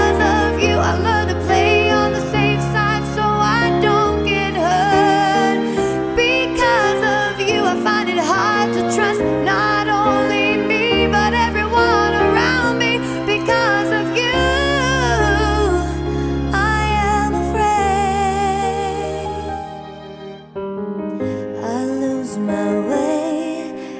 Original Female Key